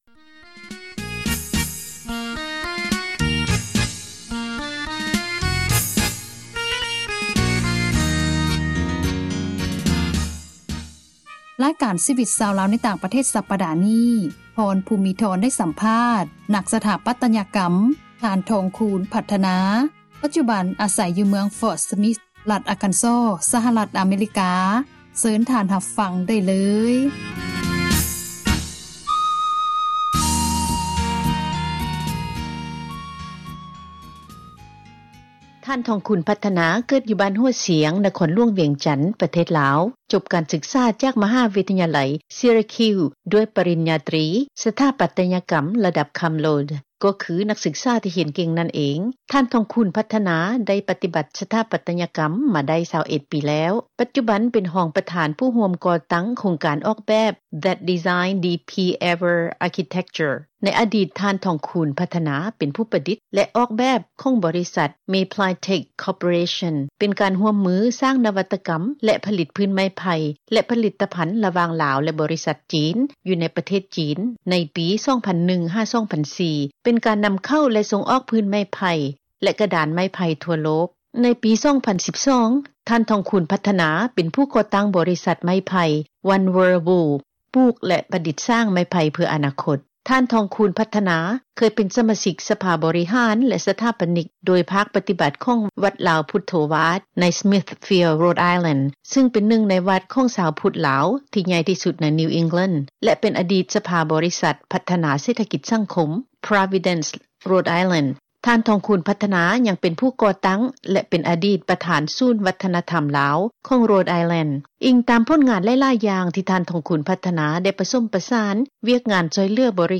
ສັມພາດ ນັກສຖາປັຕຍະກັມ